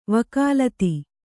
♪ vakālat